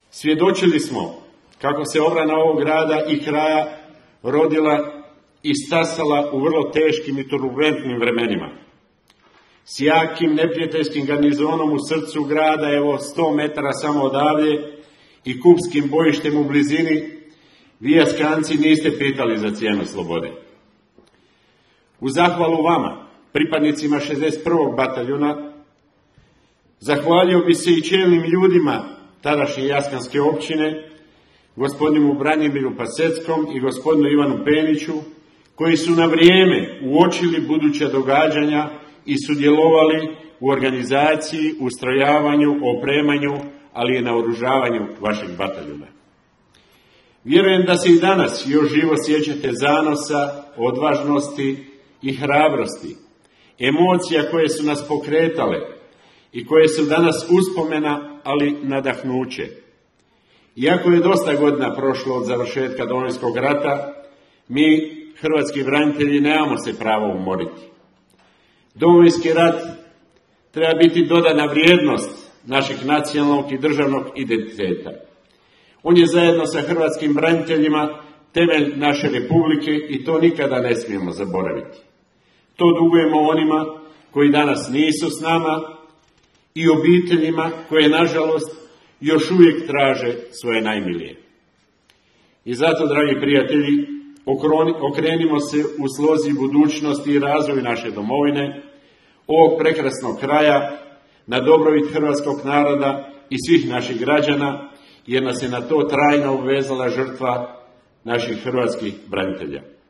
U Jastrebarskom je danas održana velika proslava 30. obljetnice od početka Domovinskog rata i prvog postrojavanja 61. Bojne ZNG RH.
Današnju proslavu organizirao je Grad Jastrebarsko pod pokroviteljstvom predsjednika RH Zorana Milanovića čiji je izaslanik na svečanosti bio Marijan Mareković – umirovljeni general pukovnik i posebni predsjednikov savjetnik za veterane domovinskog rata.